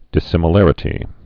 (dĭ-sĭmə-lărĭ-tē)